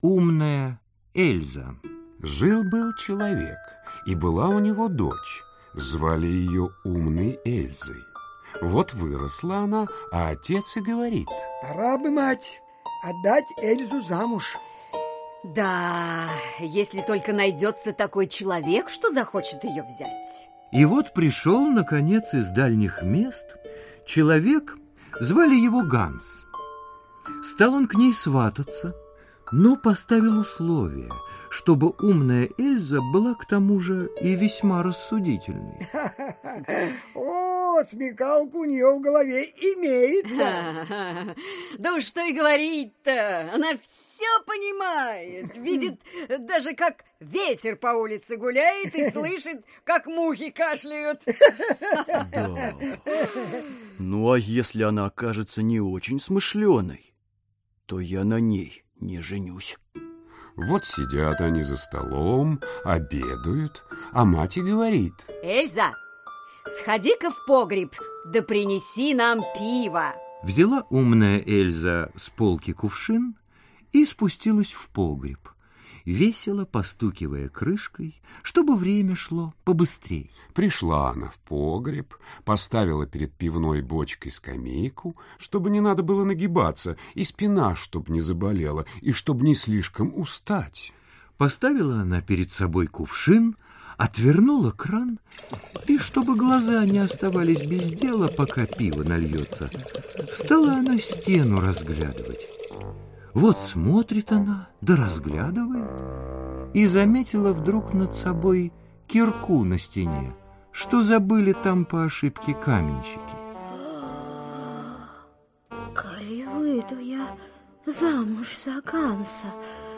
Аудиокнига Сказки Братьев Гримм в аудиоспектаклях | Библиотека аудиокниг
Aудиокнига Сказки Братьев Гримм в аудиоспектаклях Автор Братья Гримм Читает аудиокнигу Лев Дуров.